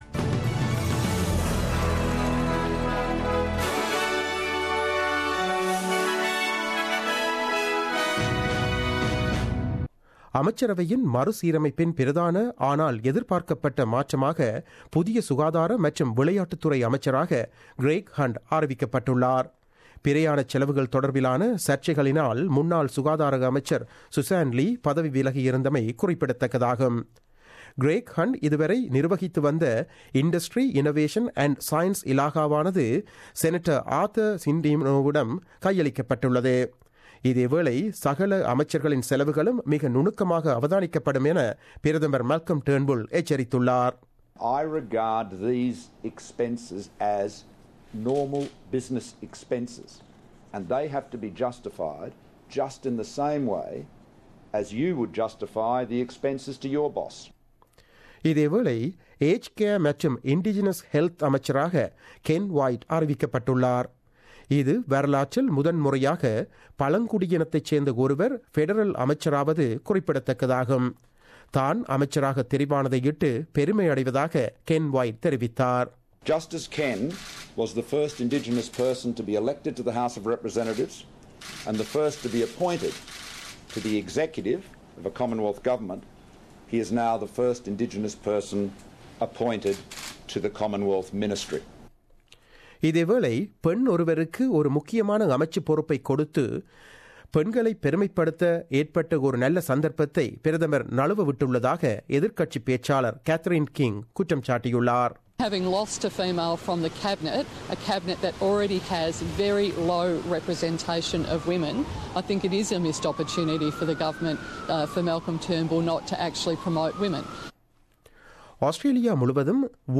The news bulletin aired on 18 January 2017 at 8pm.